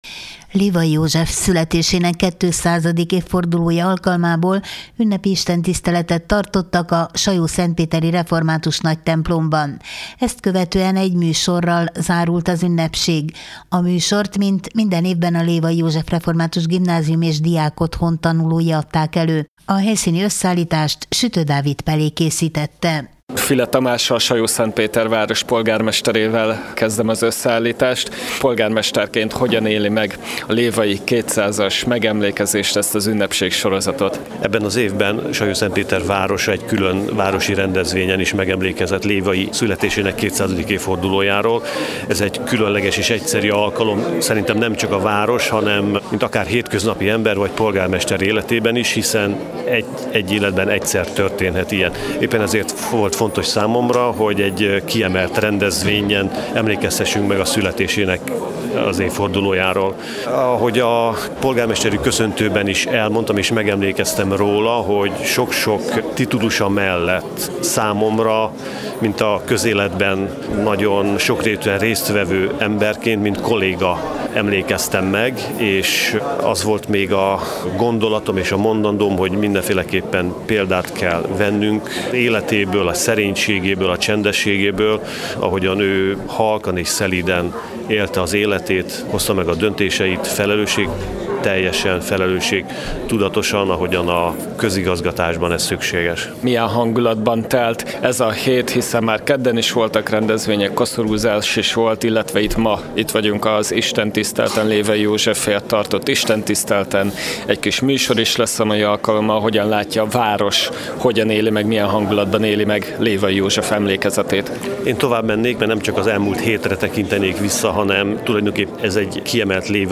Lévay József születésének 200. évfordulója alkalmából ünnepi istentisztelet tartottak a Sajószentpéteri Református Nagytemplomban, ezt követően egy műsorral zárult az ünnepség.